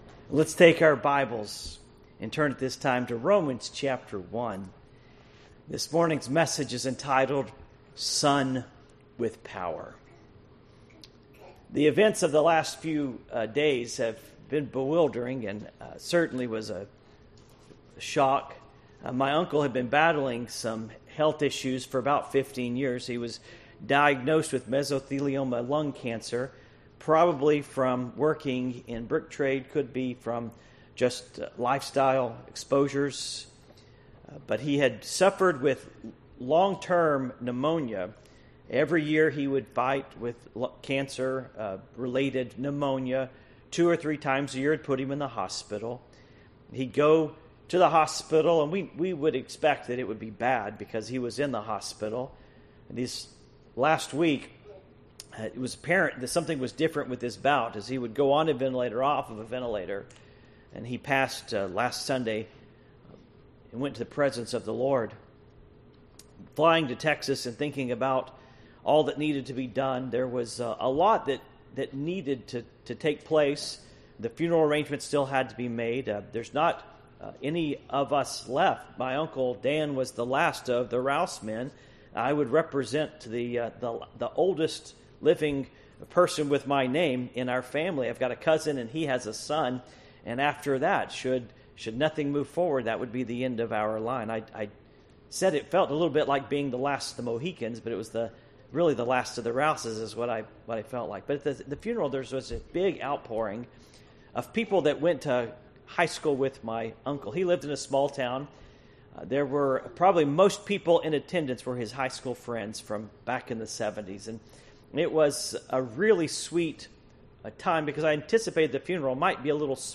Passage: Romans 1:1-4 Service Type: Morning Worship